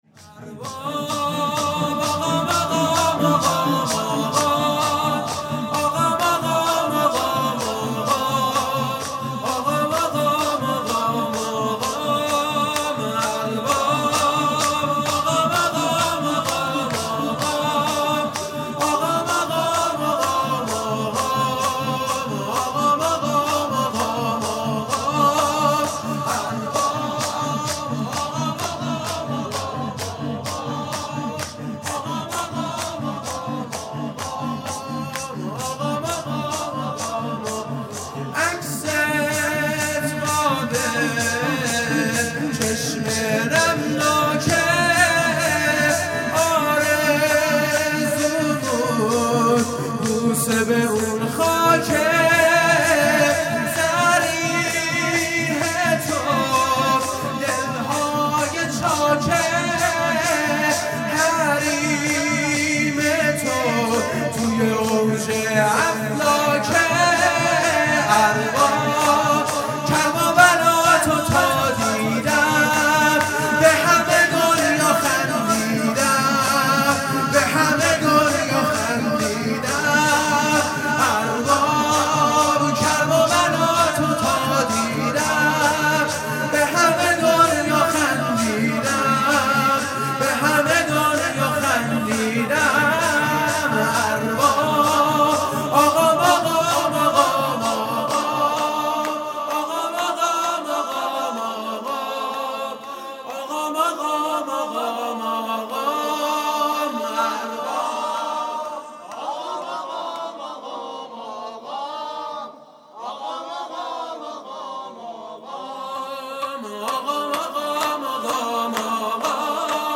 شور
شهادت حضرت زهرا (س) | ۱۹ بهمن ۱۳۹۷